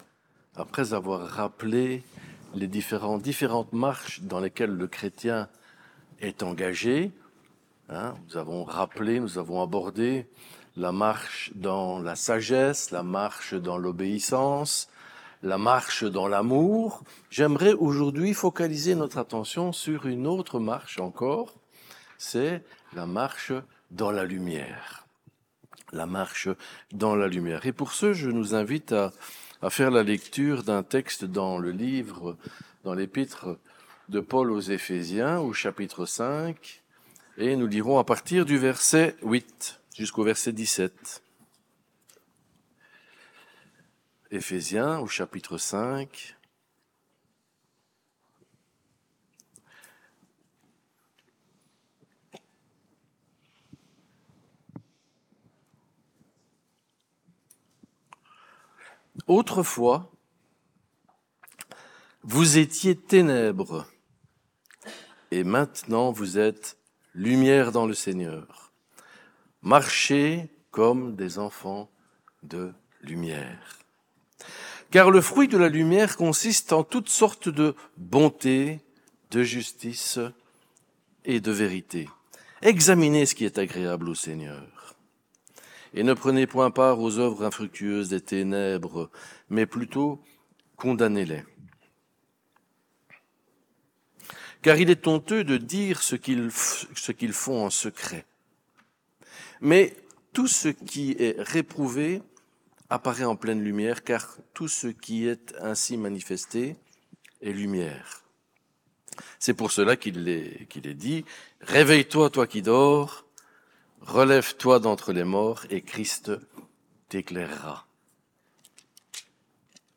Samedi a eu lieu notre convention annuelle des Assemblées Protestantes Évangéliques de Belgique.